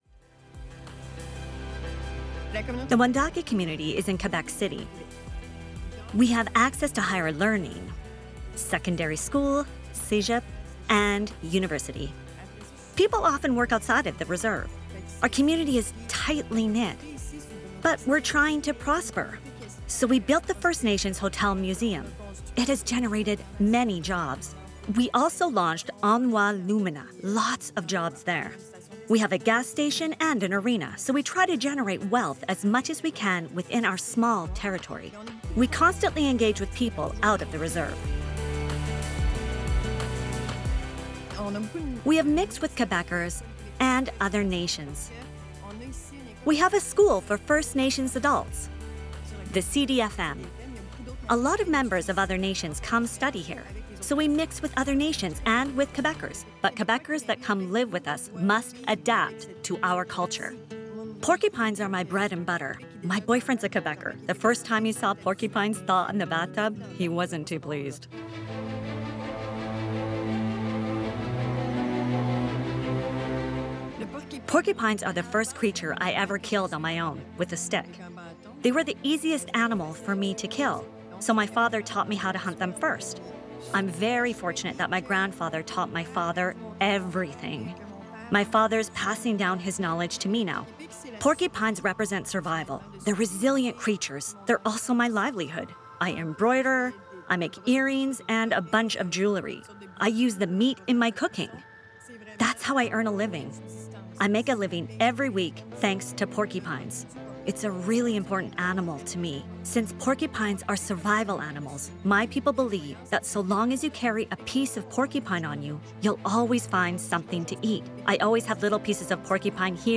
Doublage - ANG